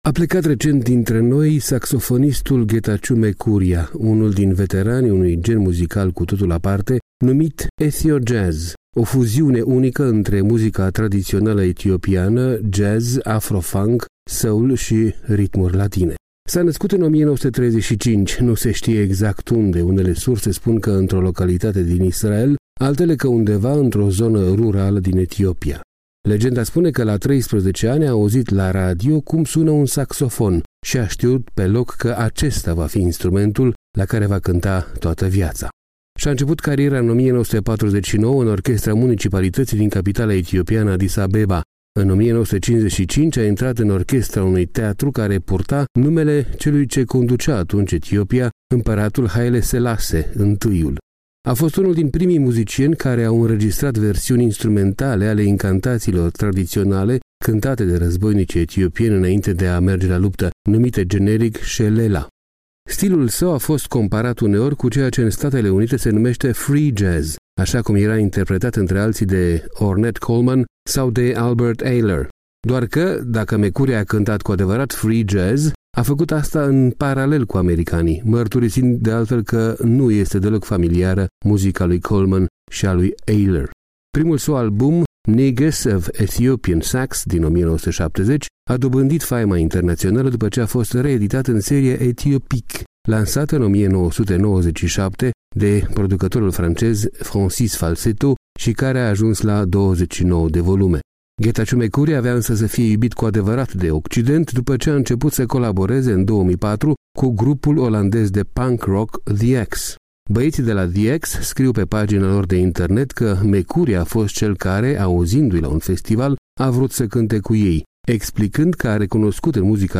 A plecat recent dintre noi saxofonistul Getatchew Mekuria, unul din veteranii unui gen muzical cu totul aparte numit ethiojazz, o fuziune unică între muzica tradițională etiopiană, jazz, afro-funk, soul și ritmuri latine.
Stilul său a fost comparat uneori cu ceea ce în Statele Unite se numește free jazz, așa cum era intrepretat între alții de Ornette Coleman sau Albert Ayler.